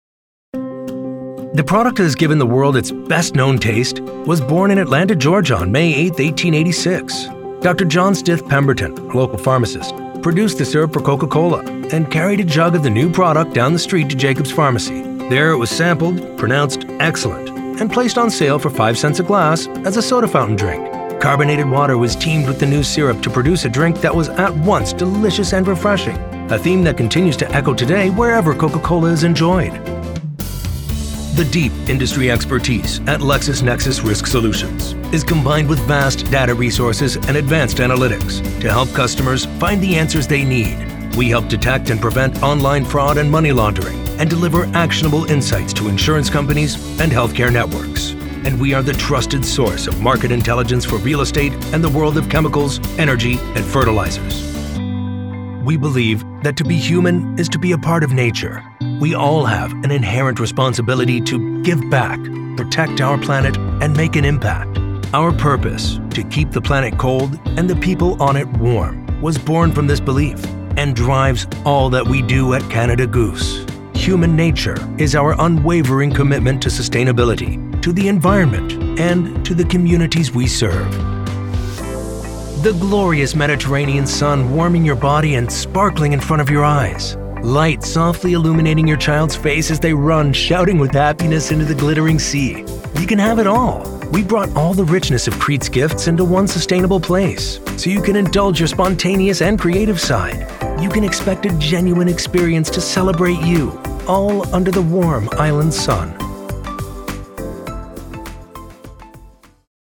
English (American)
Deep, Natural, Distinctive, Versatile, Warm
Corporate